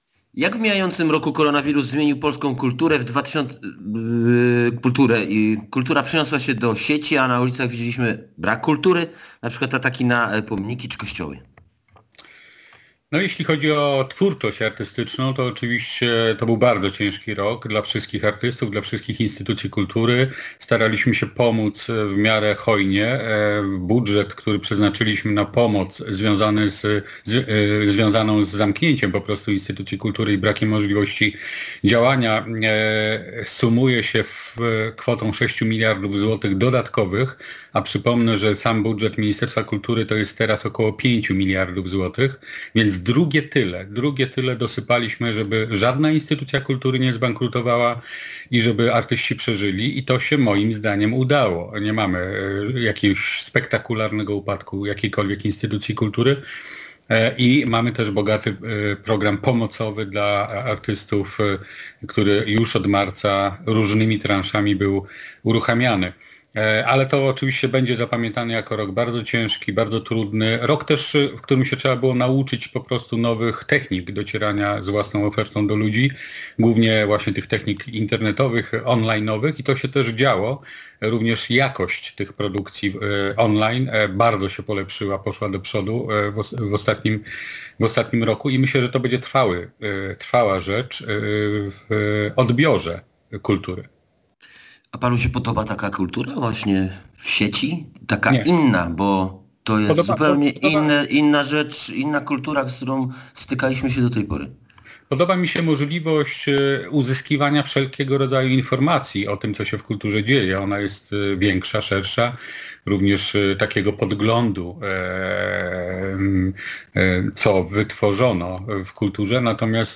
Między innymi o to pomorskiego posła PiS, wiceministra kultury, dziedzictwa narodowego i sportu Jarosława Sellina zapytał